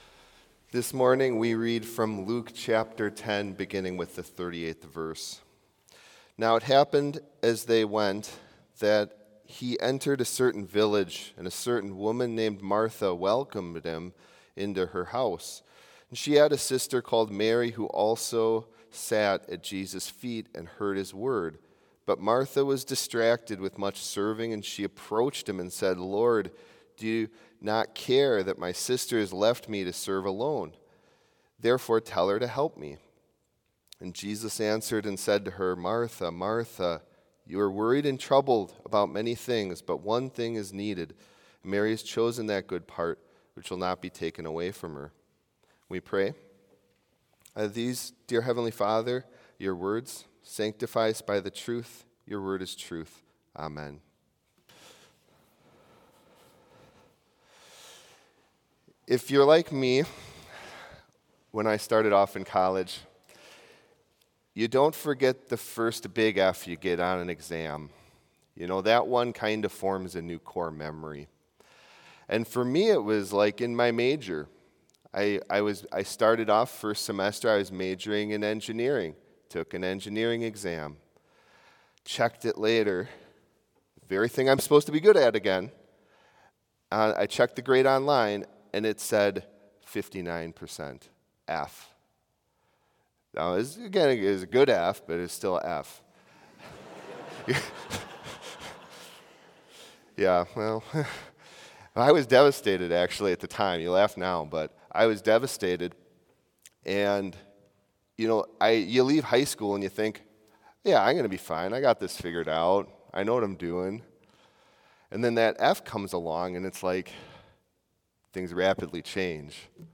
Complete service audio for Chapel - Wednesday, September 11, 2024